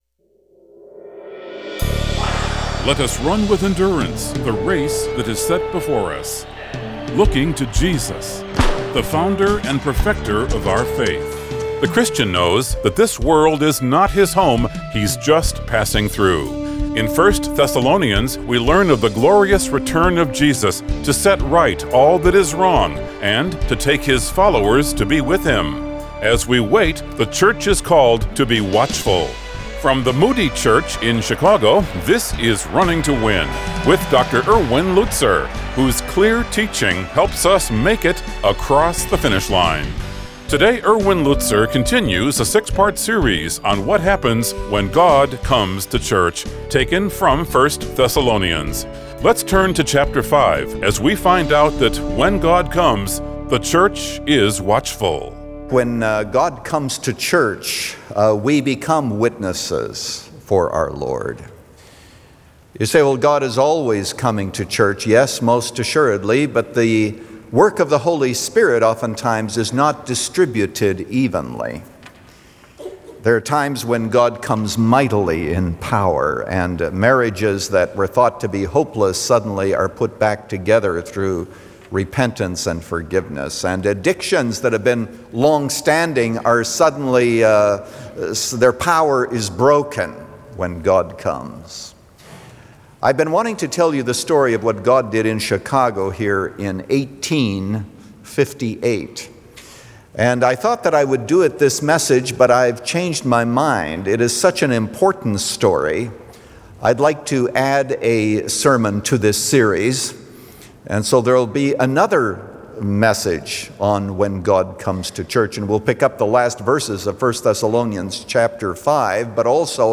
The Church Is Watchful – Part 1 of 3 | Radio Programs | Running to Win - 15 Minutes | Moody Church Media